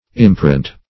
Search Result for " imperant" : The Collaborative International Dictionary of English v.0.48: Imperant \Im"pe*rant\, a. [L. imperans, p. pr. of imperare to command.]